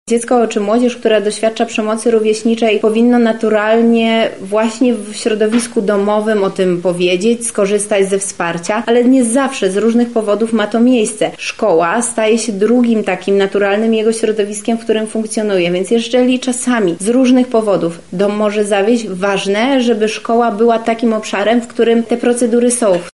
-mówi Beata Stepaniuk-Kuśmierzak, Zastępczyni Prezydenta Lublina.